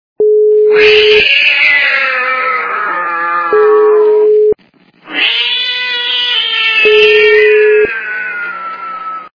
» Звуки » Природа животные » Воли злого кота - М-я-у!
При прослушивании Воли злого кота - М-я-у! качество понижено и присутствуют гудки.
Звук Воли злого кота - М-я-у!